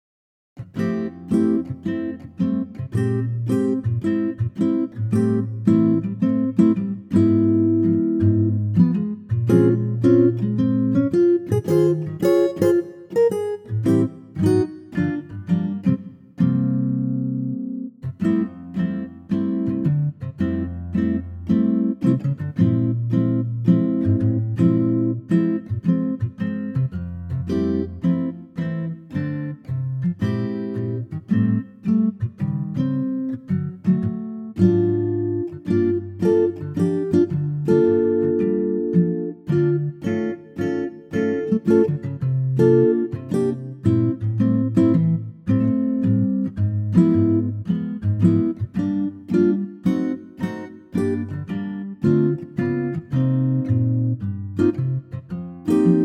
key F 3:07
key - F - vocal range - Ab to D